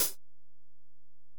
Index of /kb6/Alesis_DM-PRO/HiHat
HiHat_Region_021.wav